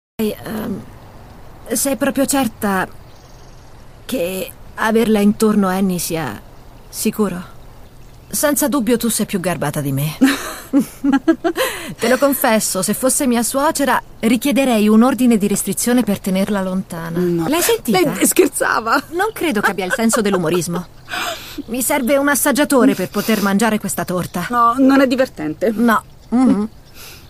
• "La fortuna di Nikuko" (Voce narrante)